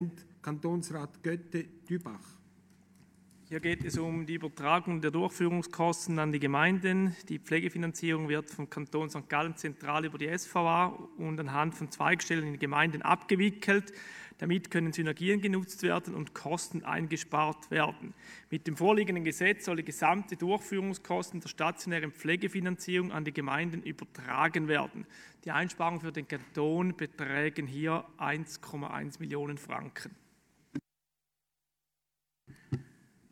Session des Kantonsrates vom 18. bis 20. Mai 2020, Aufräumsession